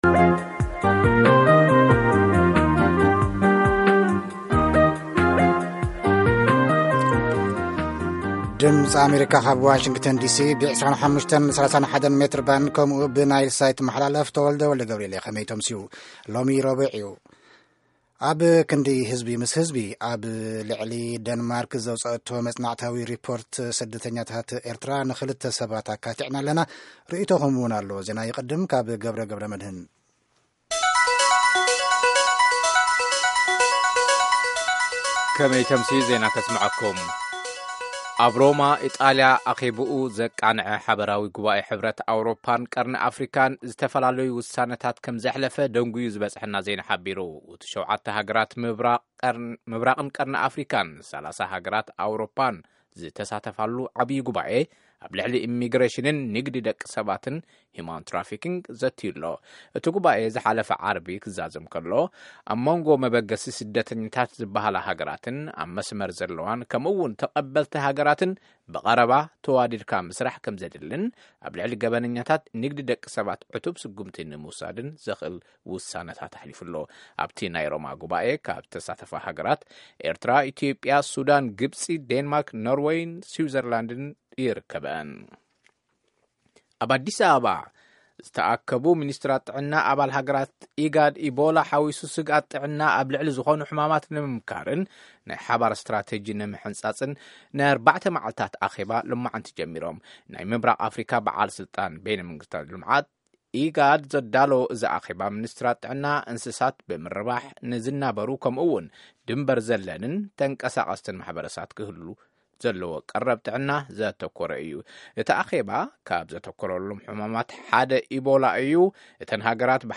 ፈነወ ትግርኛ ብናይ`ቲ መዓልቲ ዓበይቲ ዜና ይጅምር፡ ካብ ኤርትራን ኢትዮጵያን ዝረኽቦም ቃለ-መጠይቓትን ሰሙናዊ መደባትን የኸትል